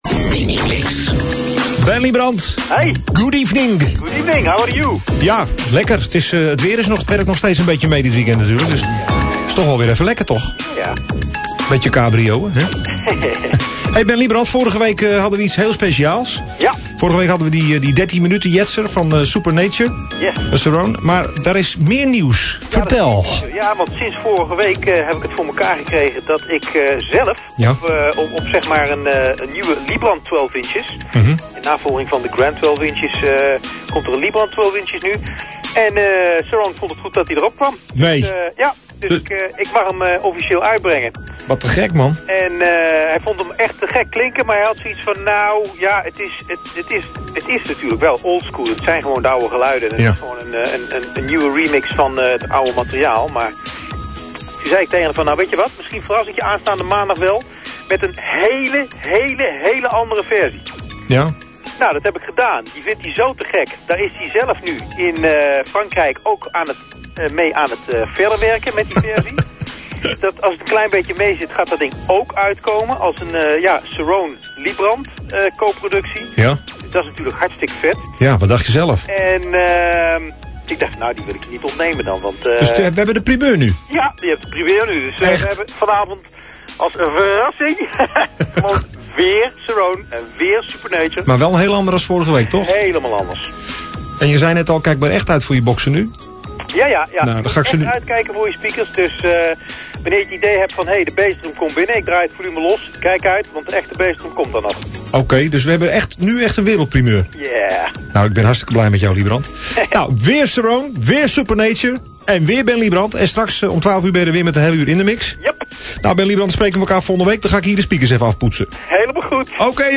Minimix / Mash-Up 2008